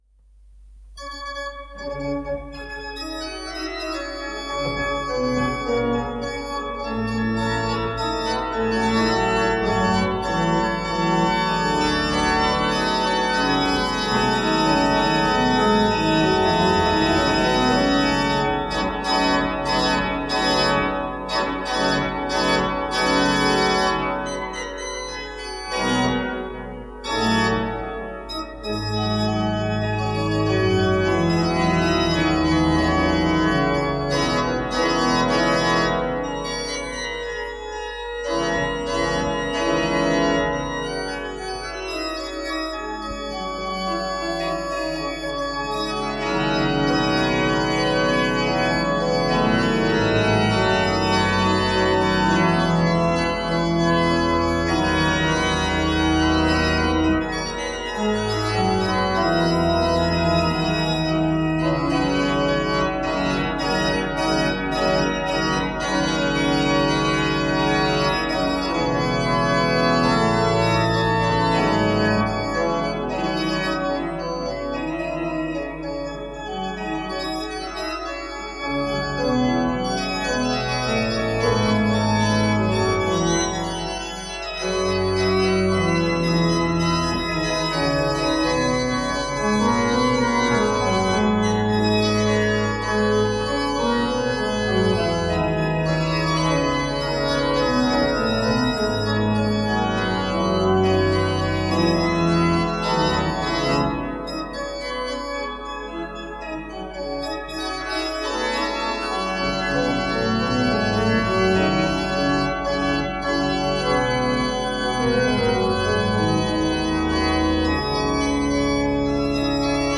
Erntedank im November - eine Predigt